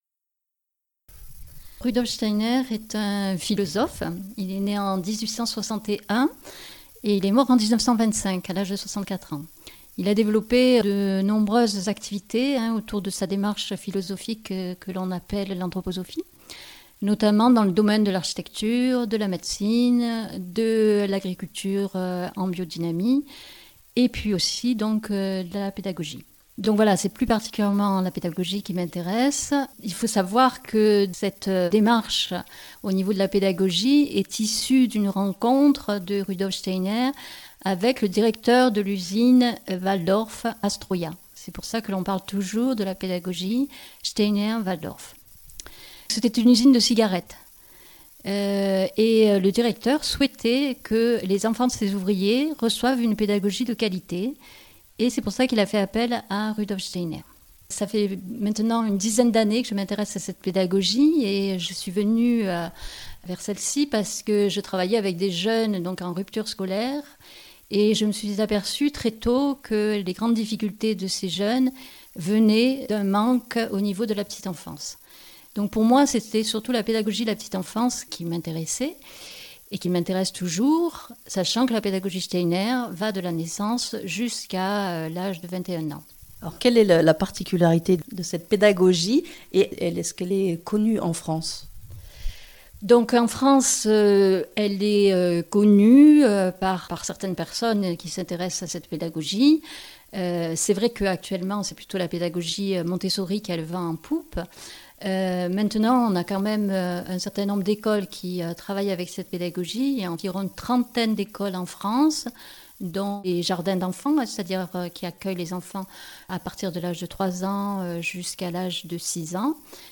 A Digne, Rencontre avec une formatrice Steiner